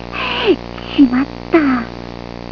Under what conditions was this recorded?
Well here are my (low quality) sounds.